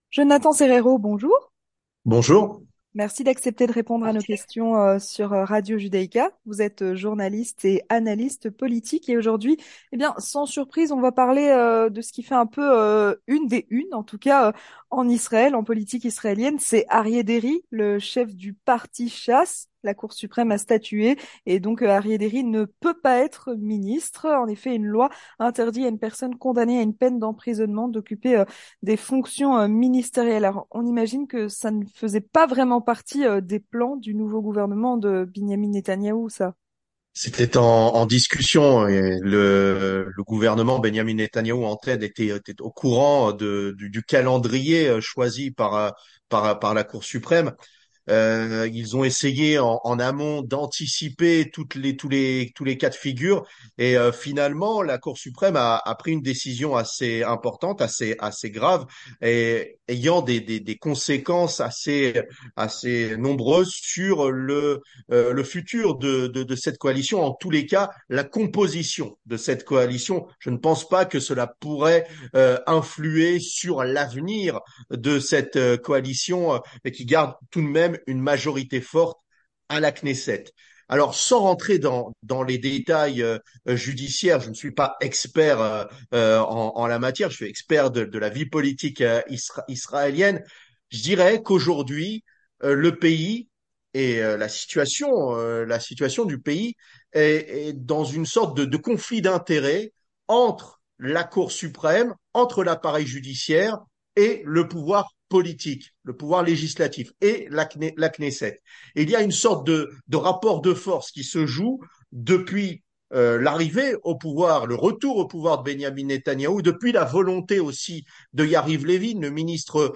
journaliste et analyste de la politique israélienne